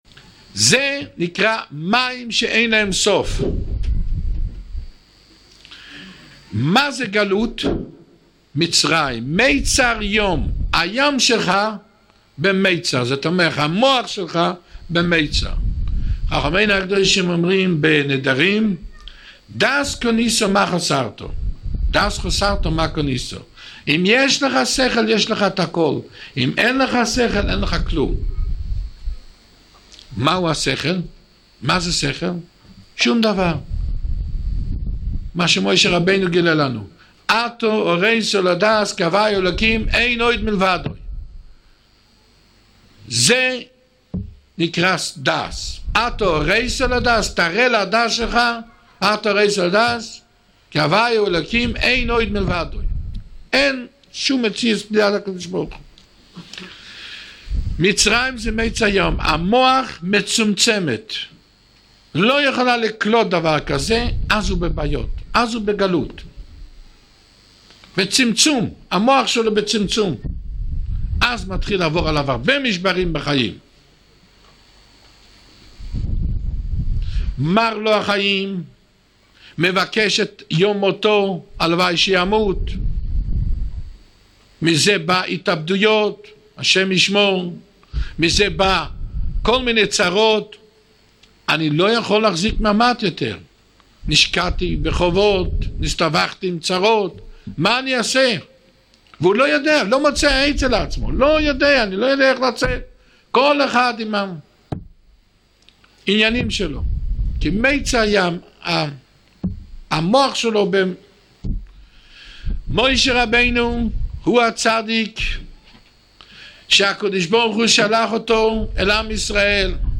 במערכת חסדי נעמי שעורי תורה מפי רבנים שונים